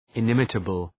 Προφορά
{ı’nımıtəbəl}